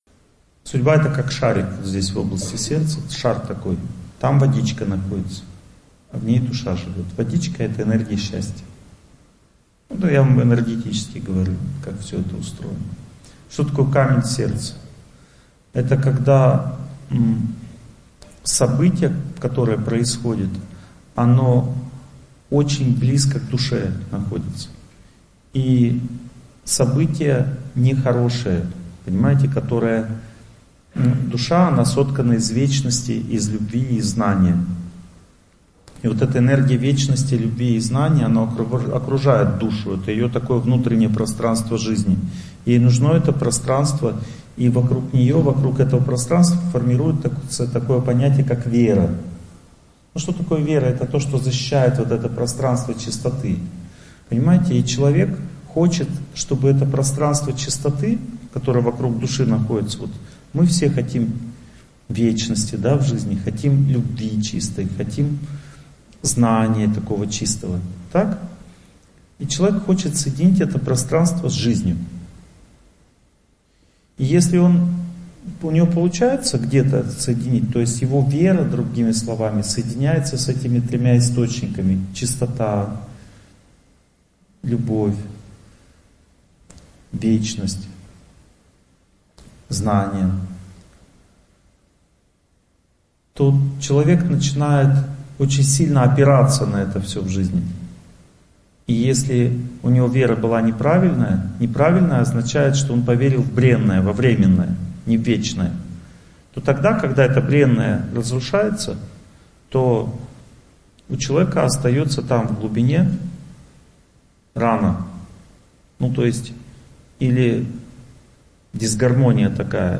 Аудиокнига Как убрать тяжелую обиду | Библиотека аудиокниг